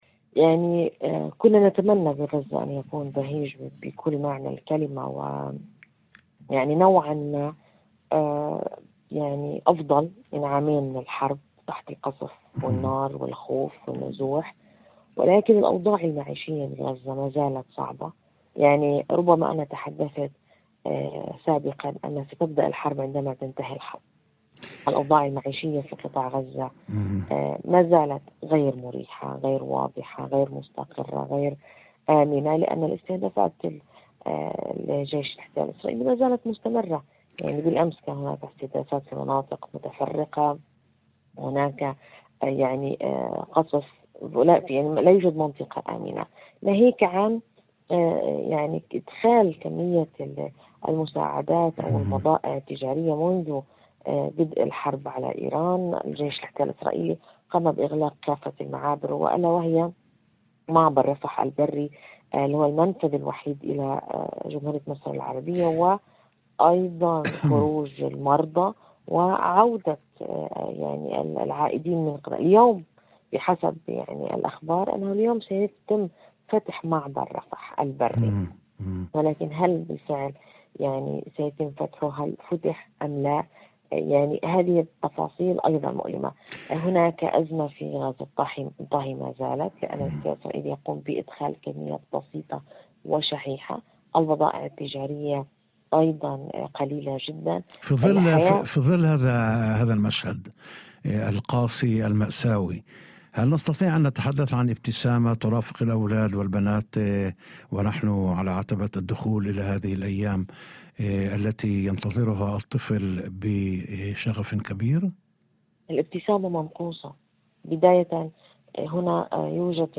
وأضافت في مداخلة هاتفية ضمن برنامج "يوم جديد" ، على إذاعة الشمس، أن أزمة غاز الطهي لا تزال قائمة، إلى جانب نقص واضح في السلع الأساسية، ما يزيد من صعوبة الحياة اليومية.